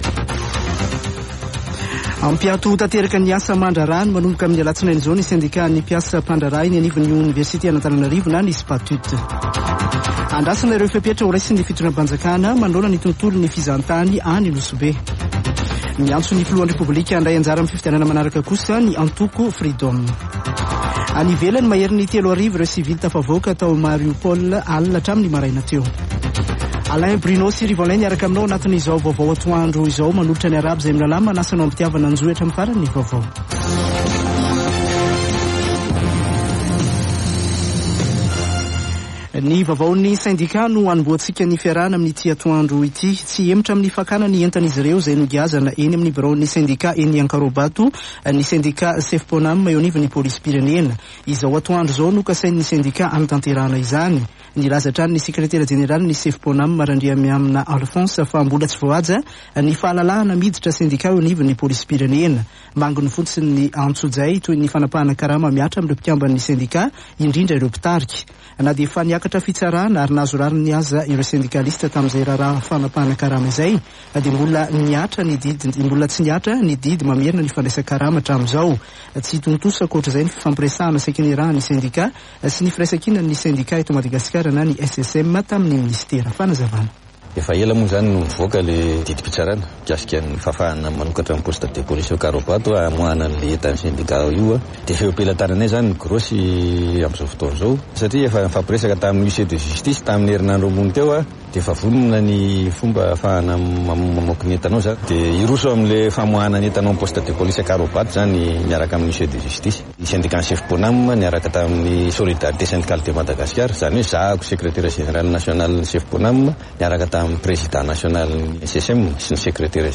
[Vaovao antoandro] Sabotsy 02 Avrily 2022